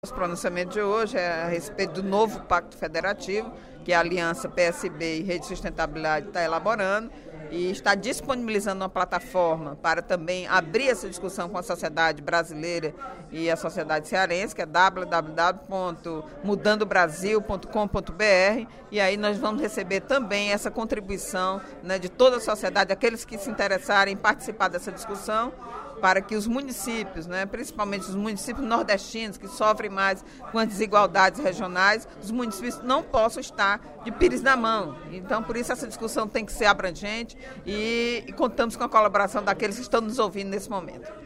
A deputada Eliane Novais (PSB) defendeu, durante o primeiro expediente da sessão plenária desta quarta-feira (11/06), a reformulação do pacto federativo no País, que está entre os desafios do programa de governo que a aliança PSB e Rede vem elaborando desde outubro.